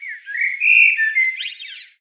На этой странице собраны натуральные звуки чириканья птиц в высоком качестве.
Звук птичьего чириканья для СМС